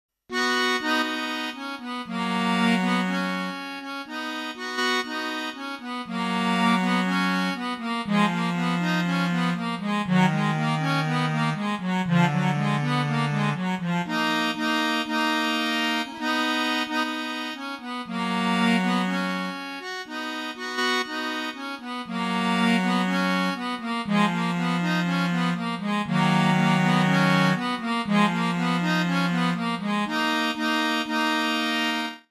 Пришлось "задействовать миди-аккордеон". Извините за качество - миди-синтезатор звуковой карты что-то мудрит((